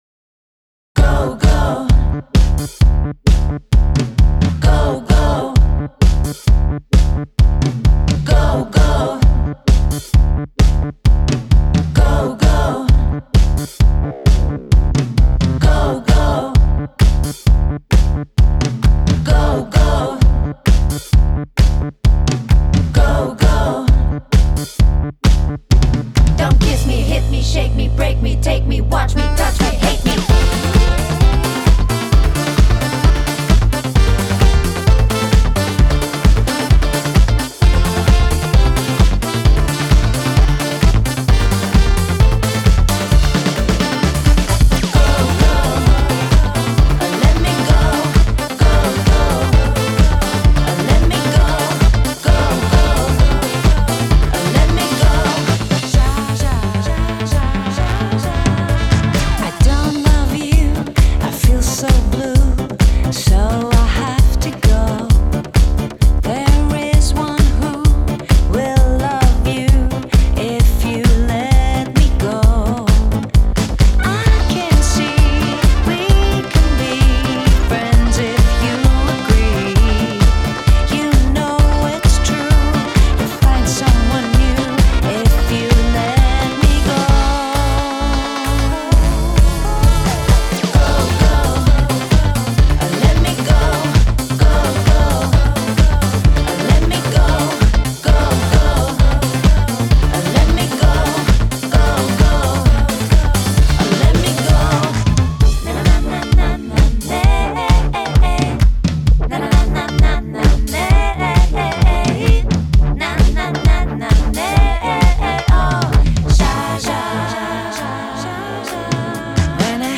Genre: Electronic, Gypsy Jazz, Balkan, Folk, World